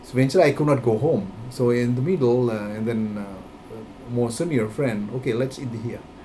S1 = Taiwanese female S2 = Indonesian male Context: S2 is talking about how he learned to eat local dishes when he was living in Cairo. S2 : ... so means i could not go home (.) so in the middle er and then er (.) more senior friend okay let’s eat here Intended Words: senior Heard as: similar Discussion: One problem with this word is the use of a short vowel [ɪ] rather than [i:] in the first syllable.